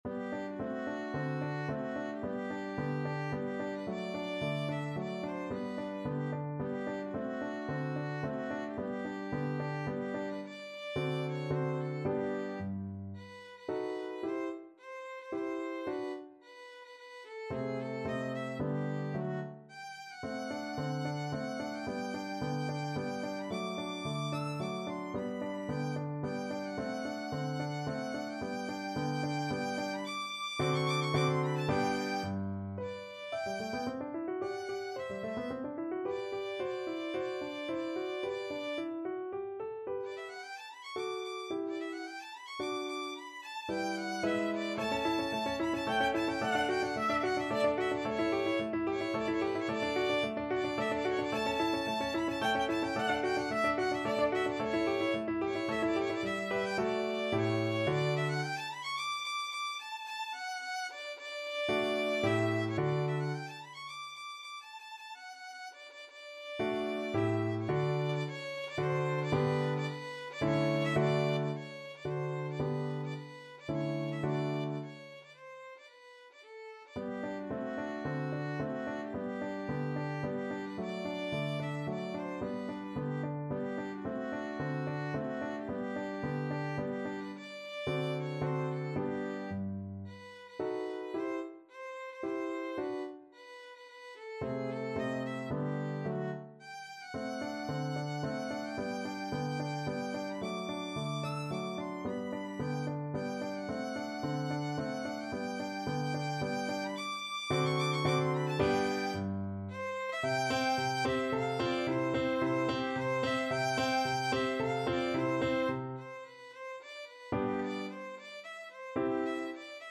Violin
3/4 (View more 3/4 Music)
G major (Sounding Pitch) (View more G major Music for Violin )
II: Tempo di Menuetto =110
Classical (View more Classical Violin Music)